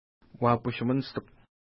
Uapush-minishtik u Next name Previous name Image Not Available ID: 241 Longitude: -60.7771 Latitude: 56.1559 Pronunciation: wa:pu:ʃu-ministuk Translation: Hare Island Official Name: Spacklins Island Feature: island (ocean)